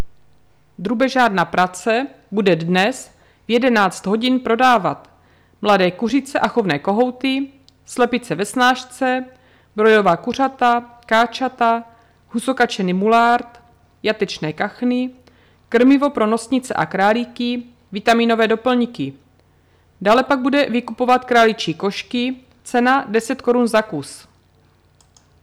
Hlášení obecního rozhlasu 9. 9. 2021